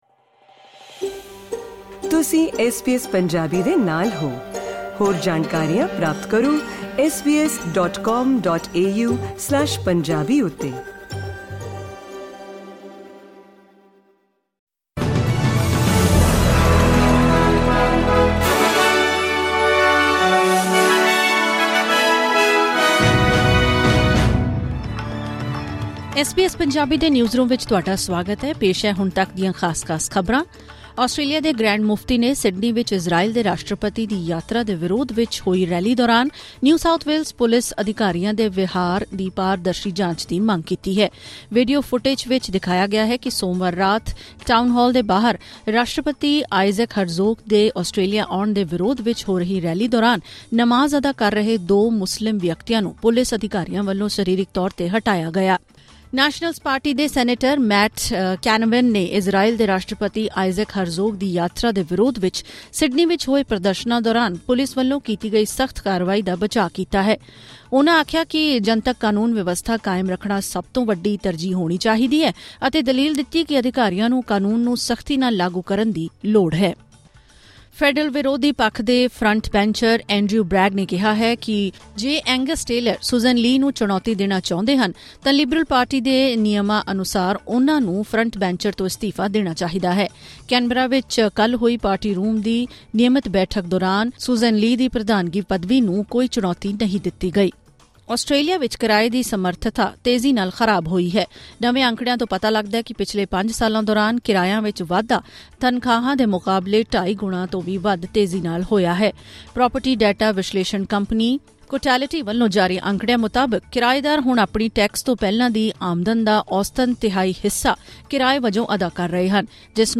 ਖ਼ਬਰਨਾਮਾ: ਸਿਡਨੀ ਰੈਲੀ ਦੌਰਾਨ ਪੁਲਿਸ ਦੀ ਸਖਤ ਕਾਰਵਾਈ ‘ਤੇ ਗ੍ਰੈਂਡ ਮੁਫ਼ਤੀ ਨੇ ਕੀਤੀ ਪਾਰਦਰਸ਼ੀ ਜਾਂਚ ਦੀ ਮੰਗ